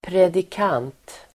Uttal: [predik'an:t]